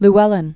(l-ĕlĭn), Richard 1906-1983.